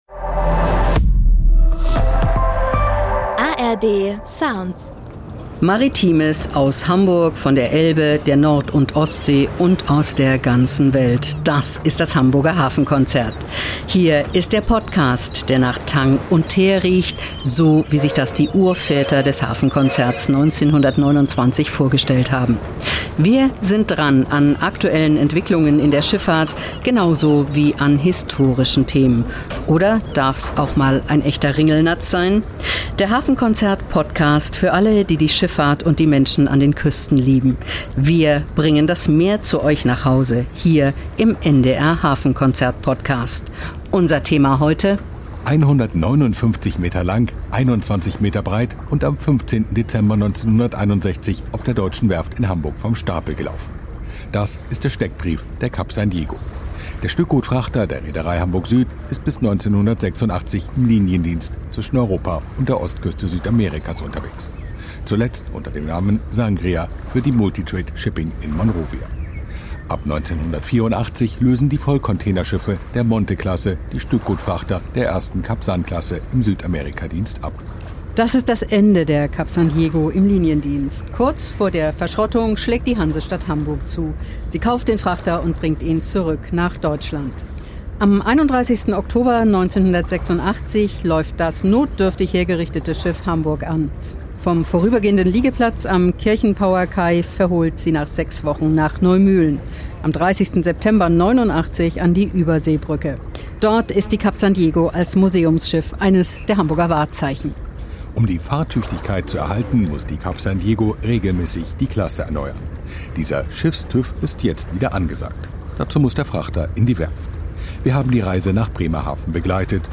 Der Hafenkonzert-Podcast begleitet die Reise von Hamburg nach Bremerhaven und schaut in der Werft genau hin.